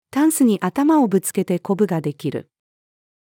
タンスに頭をぶつけてコブが出来る。-female.mp3